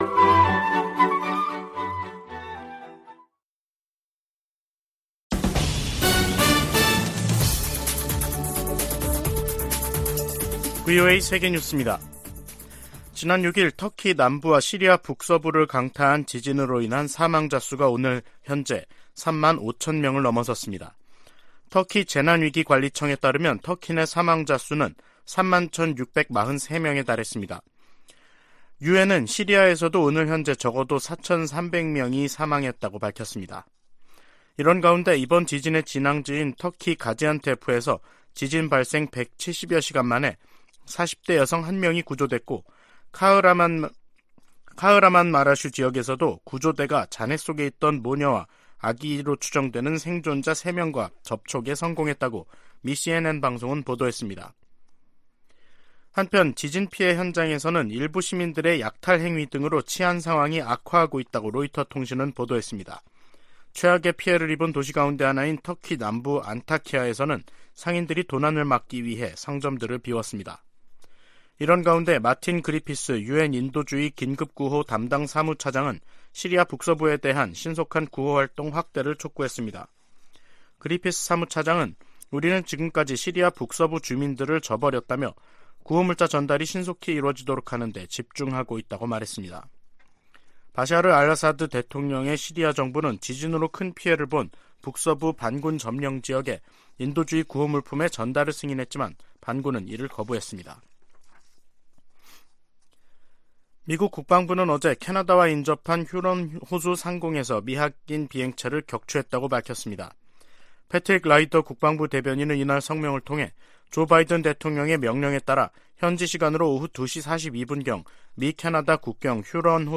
VOA 한국어 간판 뉴스 프로그램 '뉴스 투데이', 2023년 2월 13일 2부 방송입니다. 미 국무부는 북한이 고체연료 ICBM을 공개한 것과 관련해, 외교적 관여를 통한 한반도 비핵화 의지에 변함이 없다는 입장을 밝혔습니다. 북한과 러시아 간 군사협력이 한반도에도 좋지 않은 영향을 끼칠 것이라고 백악관이 지적했습니다. 한국의 남북이산가족협회가 북한 측으로부터 이산가족 문제를 토의하자는 초청장을 받았다며 방북을 신청했습니다.